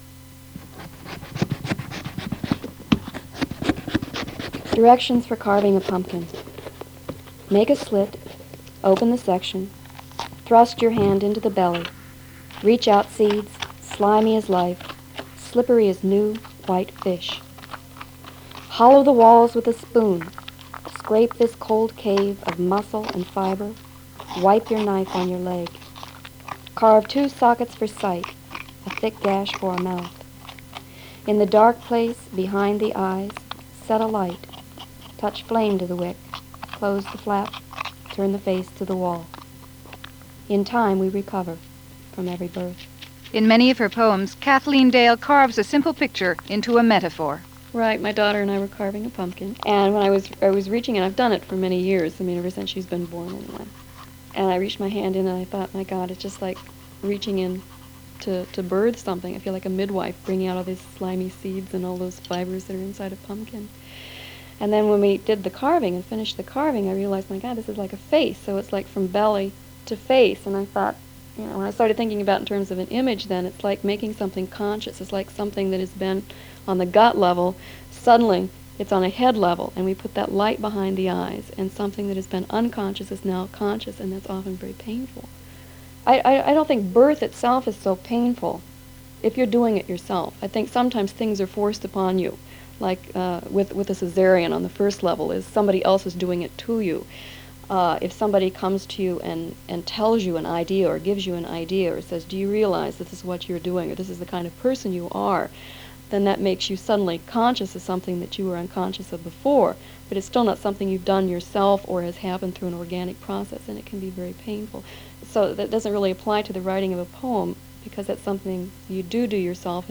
here for 1982 interview with WHA Radio